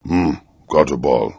gutterball-3/Gutterball 3/Commentators/Master/zen_gutterballhmm.wav at 19901ee7a9e2ec02a974f2d9b9fa785384d4e897
zen_gutterballhmm.wav